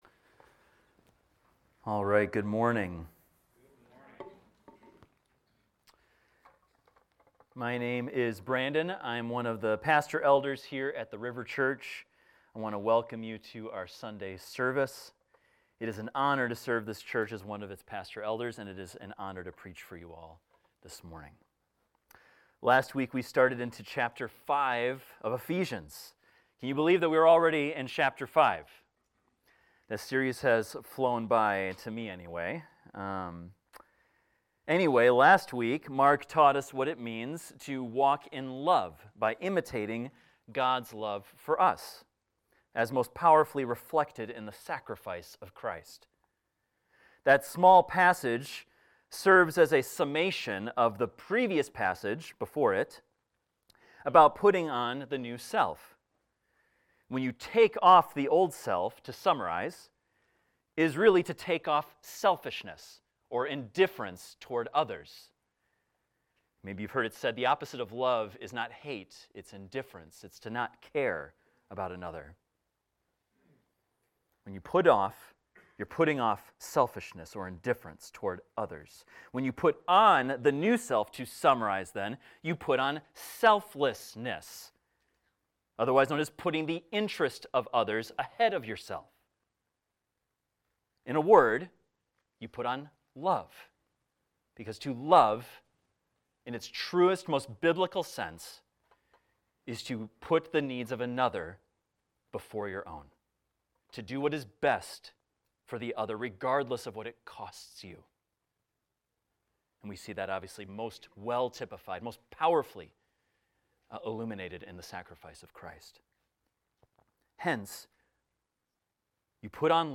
This is a recording of a sermon titled, "Children of Light."